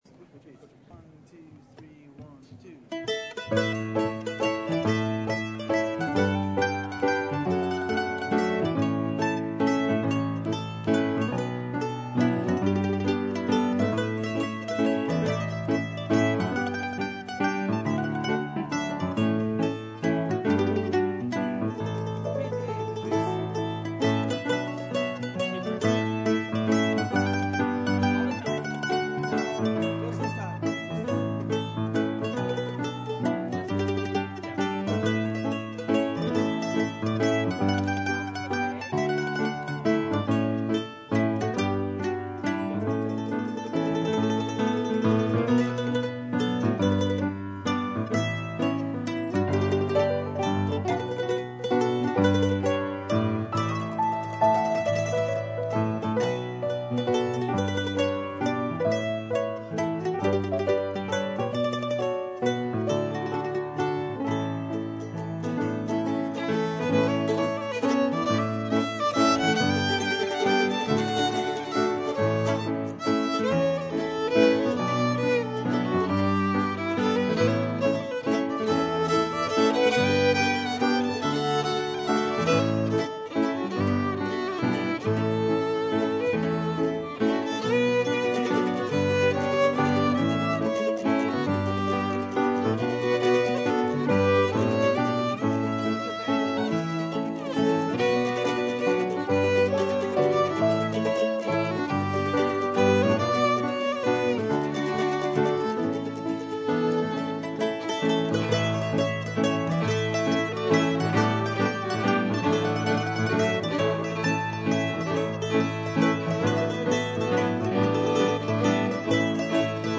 Contratopia at Glen Echo Park
This is a simple tune
She proceeds to give a beautiful demonstration of how to accompany a barn dance waltz on the piano along with a nod to the playing of the legendary Floyd Cramer.
sloppy tremelo chords
mandolin
soundboard recording